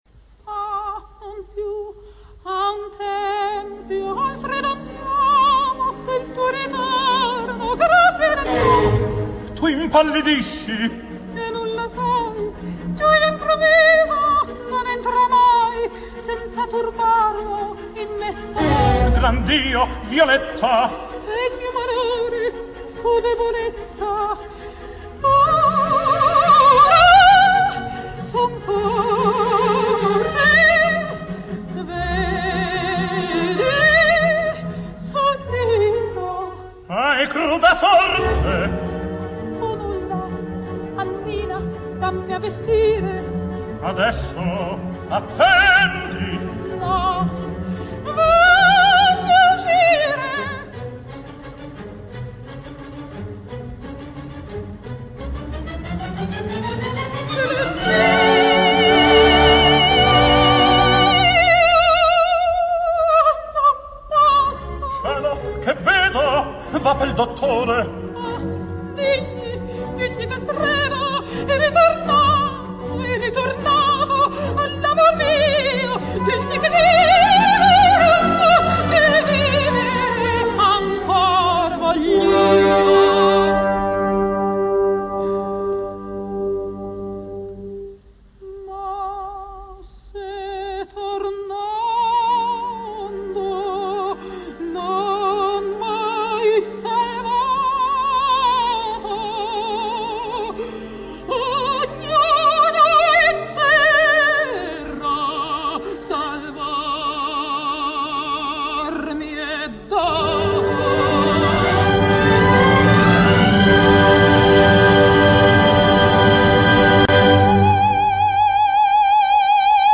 opera completa, registrazione in studio.